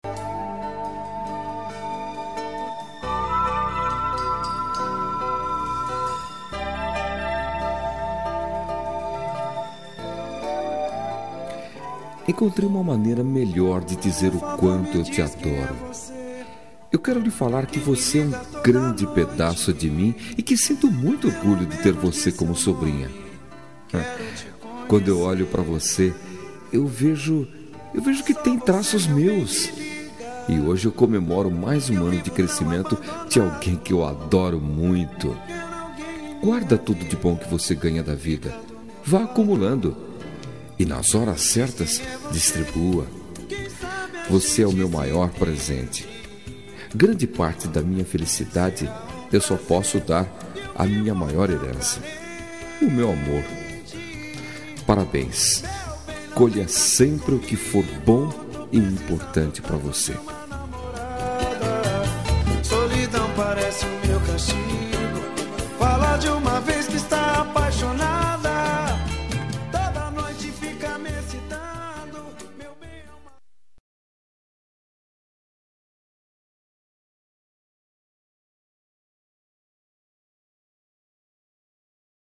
Aniversário de Sobrinha – Voz Masculina – Cód: 21490